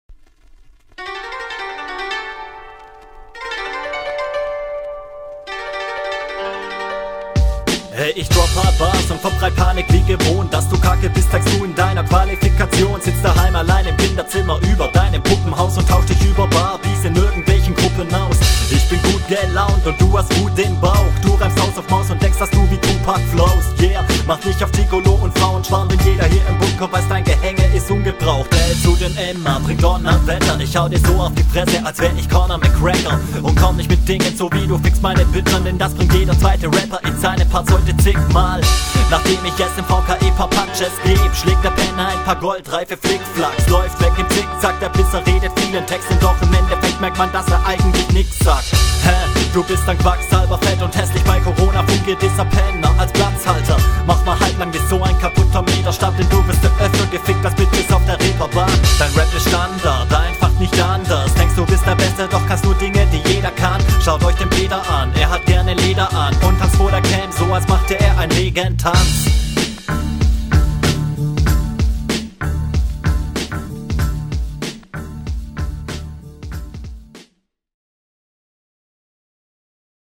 Flowlich geht die Runde sehr gut ins Ohr.
Stimme könnte etwas lauter; Linetechnisch ist bei mir jetzt allzuviel hängengeblieben.